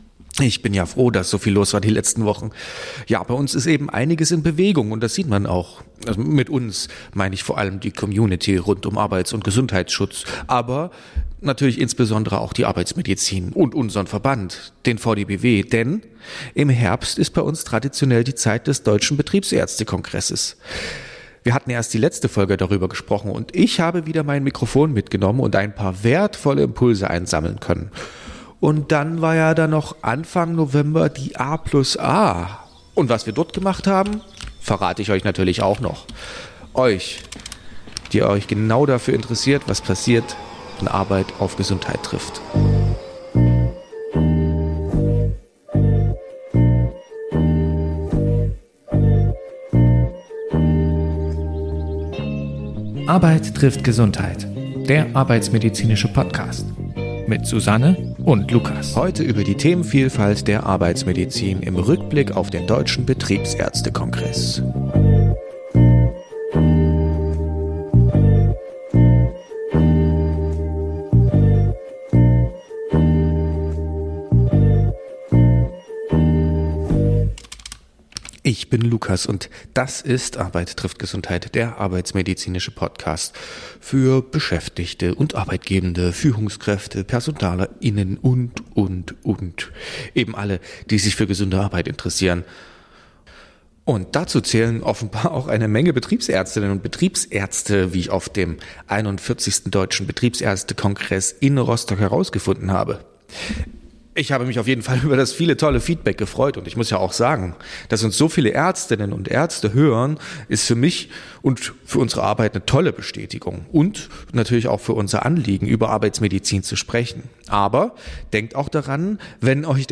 In dieser Episode erwartet Sie ein kompakter Rundumblick auf aktuelle Themen der Arbeitsmedizin – direkt eingefangen auf dem Deutschen Betriebsärzte Kongress und der A+A. Unsere Expertinnen und Experten geben Einblicke in Entwicklungen der arbeitsmedizinischen Praxis, die Bedeutung von Lebensphasen...